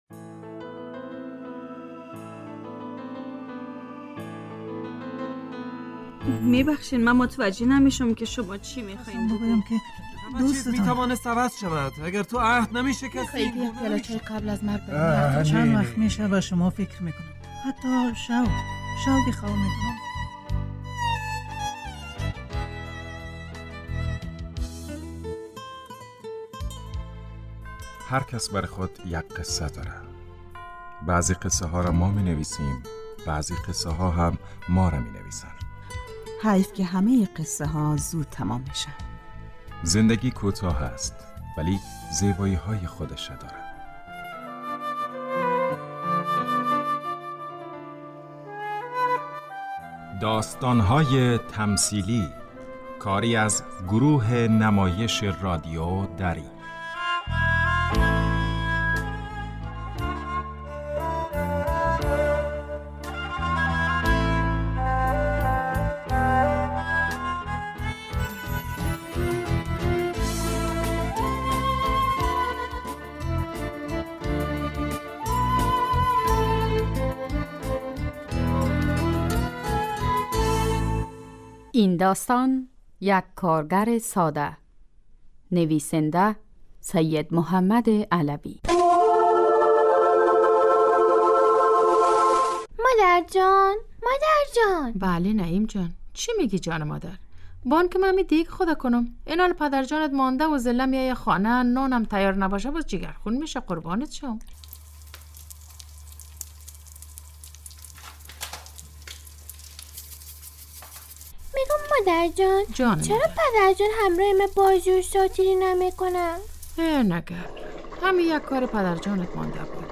داستان تمثیلی - یک کارگر ساده